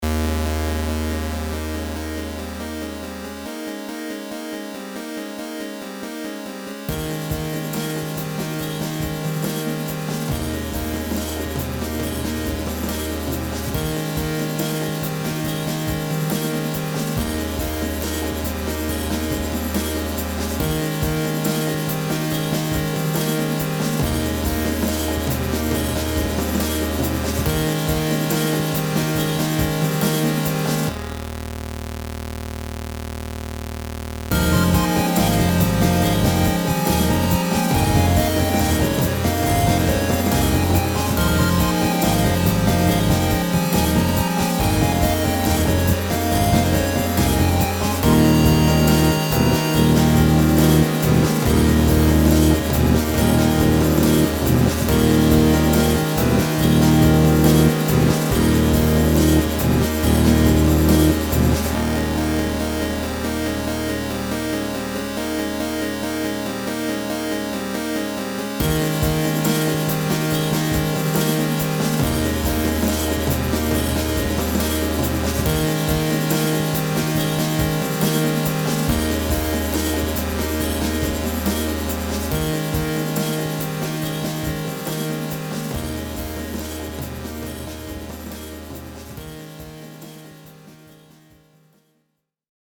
made with garageband and magical 8bit